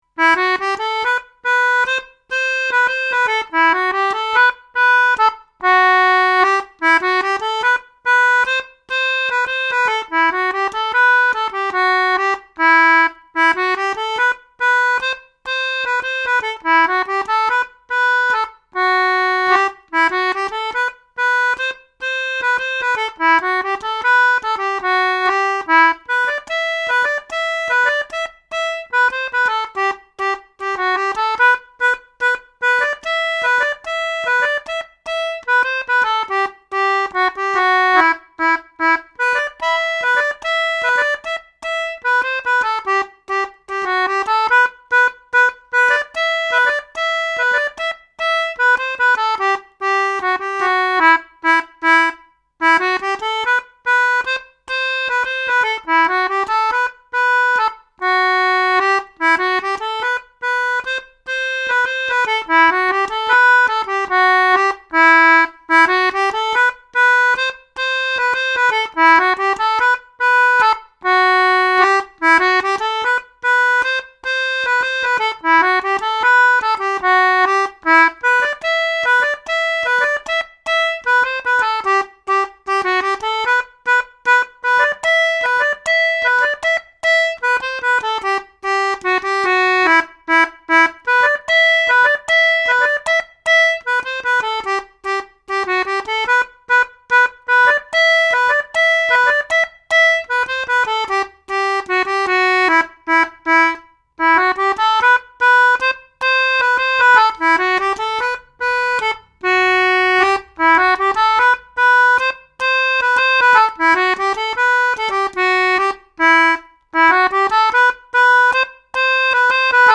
For the technically minded I used a Rode NT1A condenser mike, recording into a Fostex D90 hard disk recorder via a Soundcraft Spirit SX mixer. Mixdown was onto a PC using an Emu 0202 external interface, again via the Spirit SX with effects provided by a TC Electronic M300 unit.
Very lively and
vigorous.